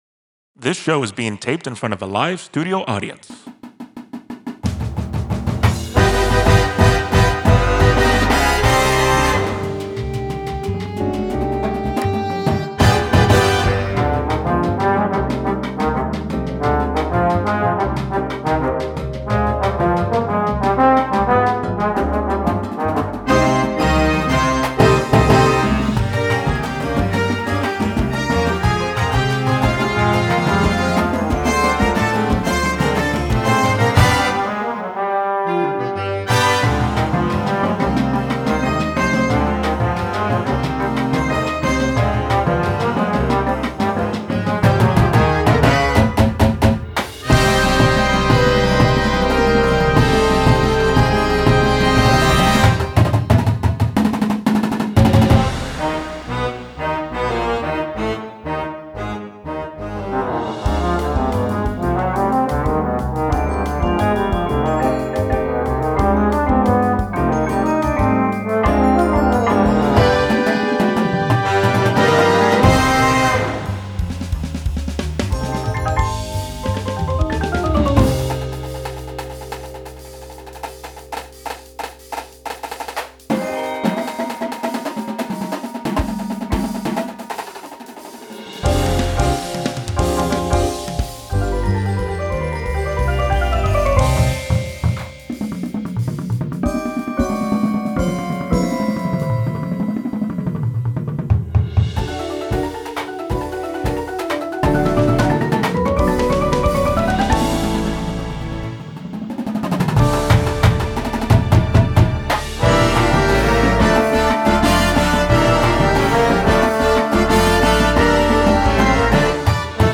Marching Band Shows
and the swing of a big band sound